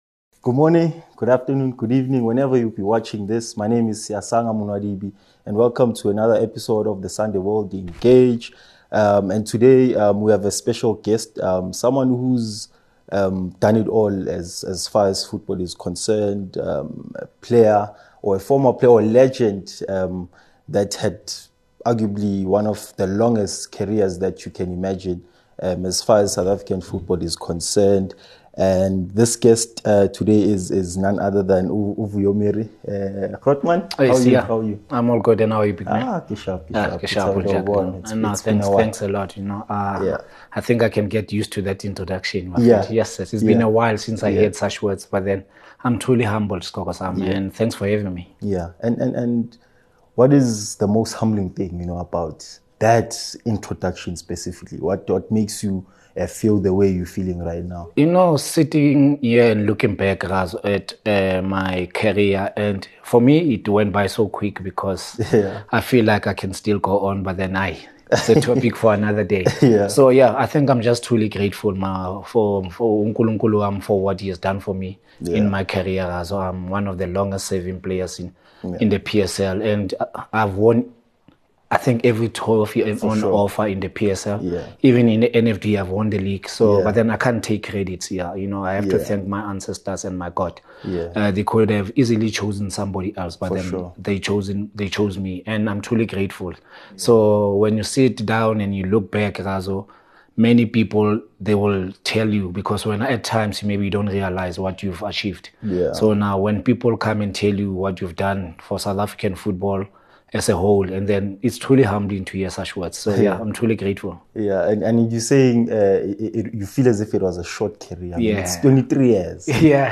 join us for an exclusive sit-down with Vuyo Mere as we unpack the highs and lows of life on and off the pitch. ⚽ From unforgettable moments in the PSL to hard-earned lessons, Vuyo shares candid insights every football fan needs to hear.
Don’t miss this inspiring conversation about the beautiful game, resilience, and giving back.